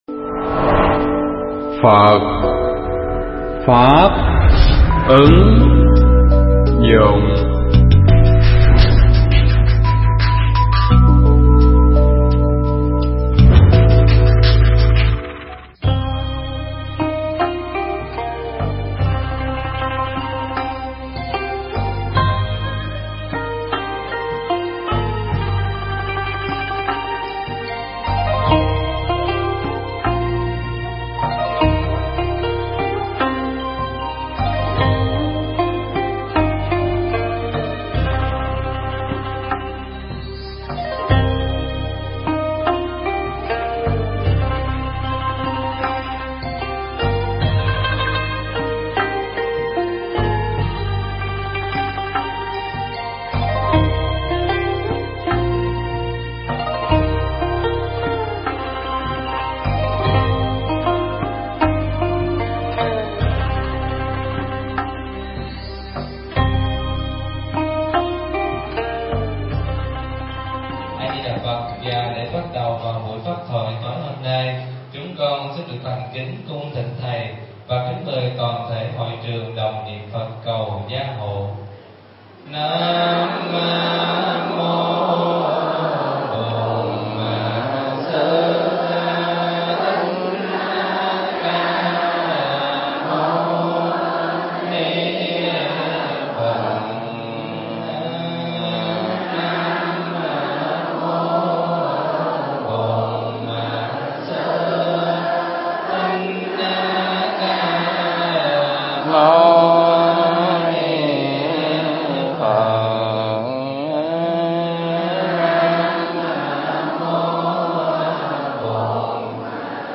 Nghe Mp3 thuyết pháp Kinh Pháp Cú Phẩm Phật Đà
Nghe bài giảng mp3 Kinh Pháp Cú Phẩm Phật Đà (Câu 183)
thuyết giảng tại tu viện Tường Vân